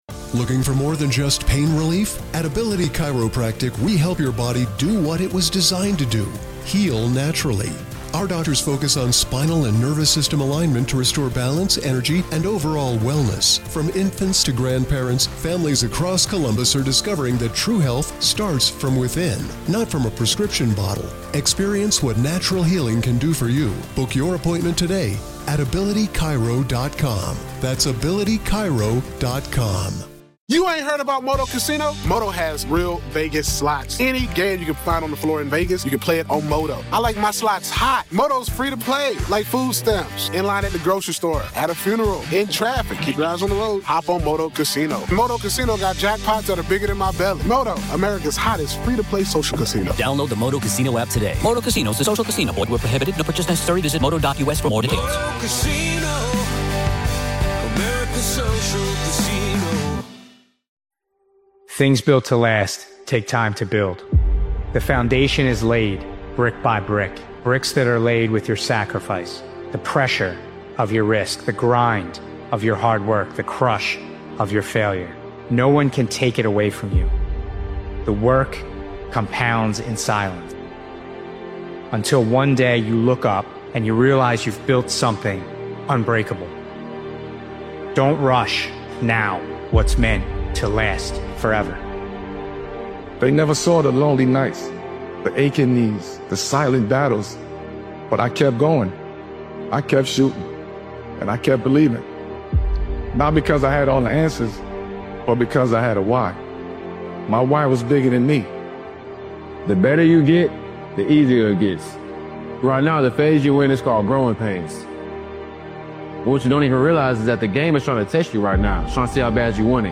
Powerful Motivational Speech Video is an uplifting and unstoppable motivational video created and edited by Daily Motivations.
This inspiring motivational speeches compilation reminds you that belief is the foundation, and consistency is the bridge between who you are and who you want to become.